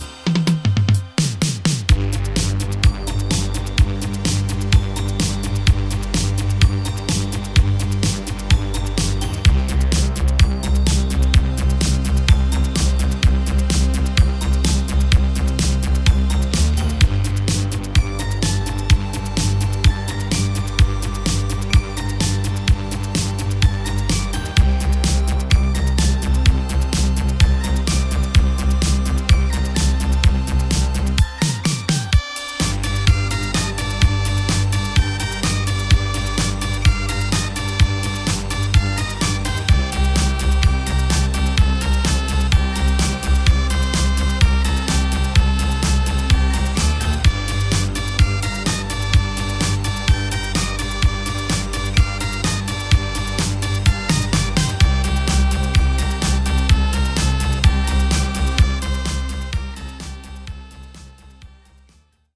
RETROCADE带有各种2x100模拟波形，从失谐的铜管声音，风琴和琴键到神秘的打击垫，滤波器扫描和嗡嗡作响的低音。 这些源波形构成了 256 个预设快照，涵盖低音、键、主音、弹拨声音、打击垫等。